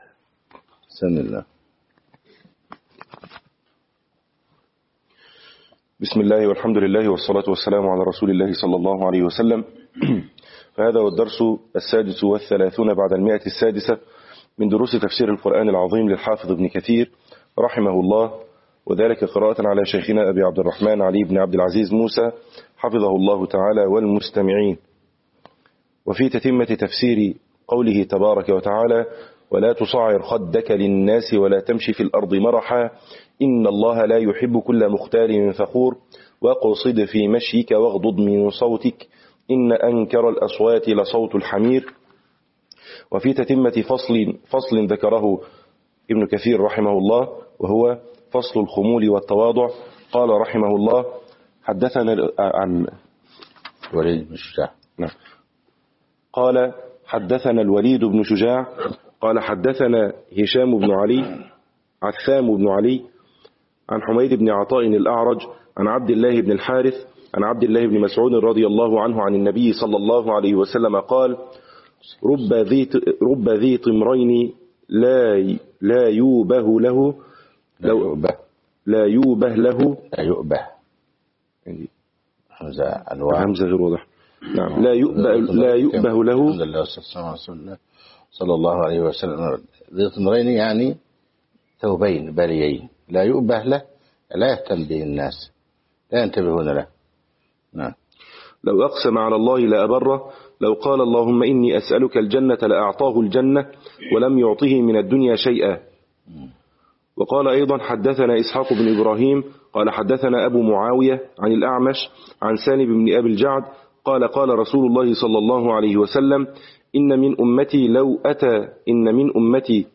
تفسير ابن كثير شرح